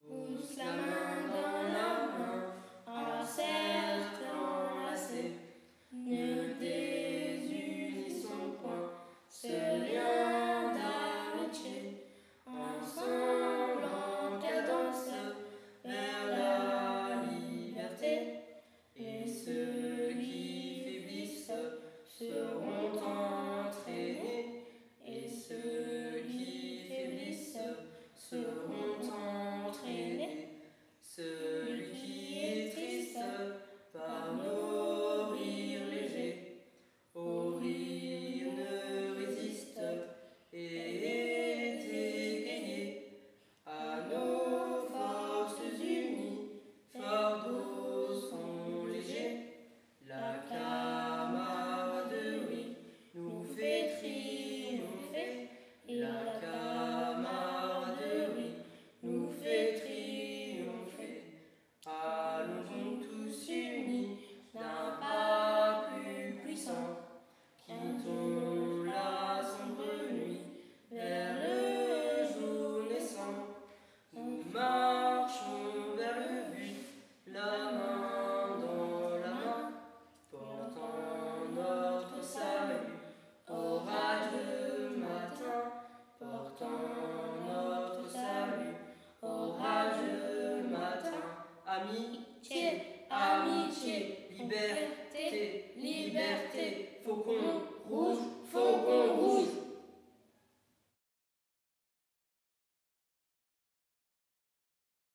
Genre : chant
Type : chant de mouvement de jeunesse
Interprète(s) : Les Faucons Rouges de Morlanwelz
Lieu d'enregistrement : Morlanwelz
Chantée à la fin des activités pendant le camp.